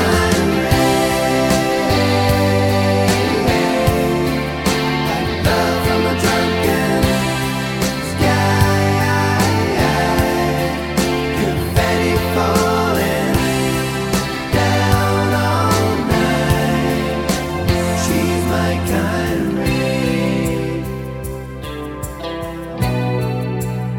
Full Version Country (Male) 4:37 Buy £1.50